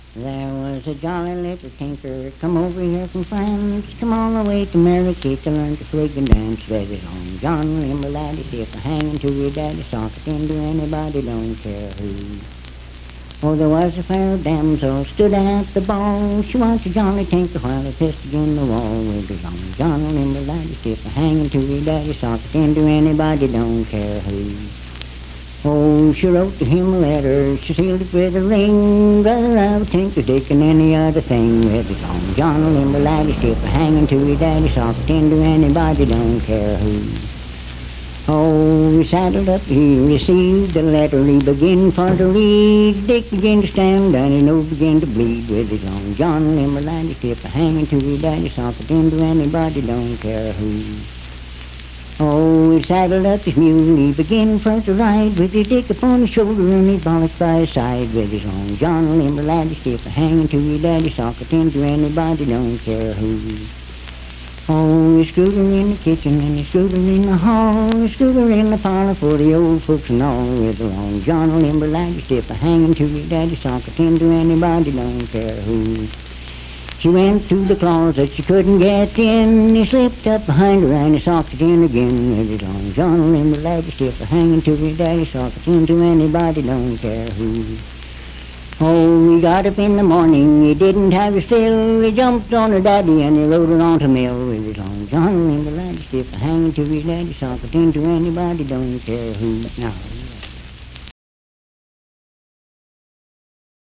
Unaccompanied vocal music
Verse-refrain 8(8w/R).
Performed in Sandyville, Jackson County, WV.
Bawdy Songs
Voice (sung)